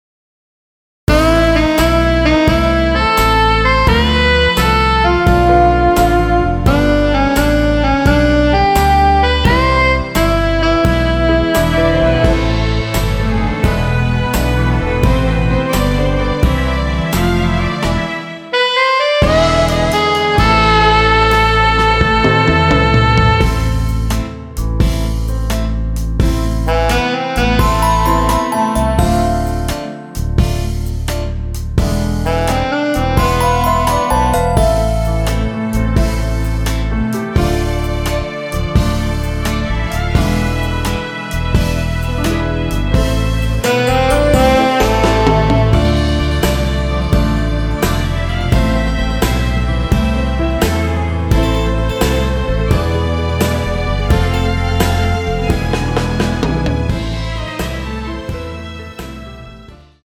남성분이 부르실 수 있는 키의 MR입니다.
원키에서(-6)내린 MR입니다.
Am
앞부분30초, 뒷부분30초씩 편집해서 올려 드리고 있습니다.
중간에 음이 끈어지고 다시 나오는 이유는